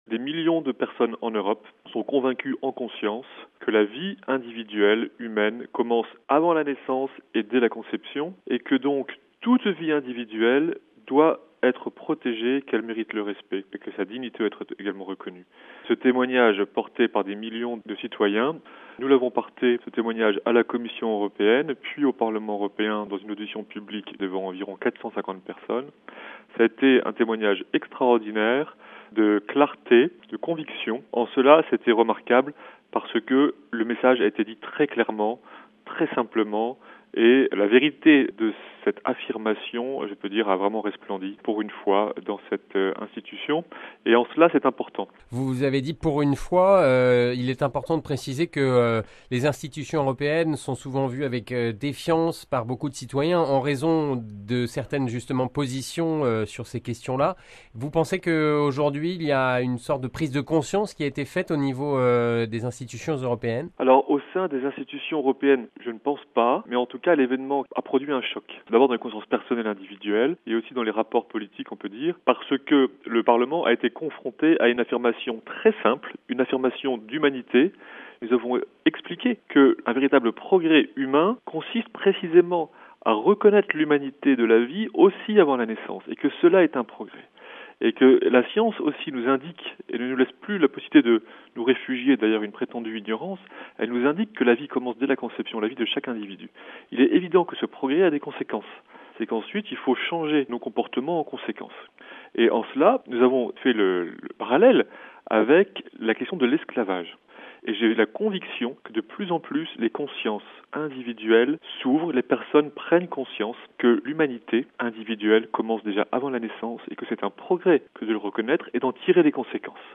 (RV) Entretien - Le Pape a accueilli vendredi matin les membres du mouvement italien pour la vie.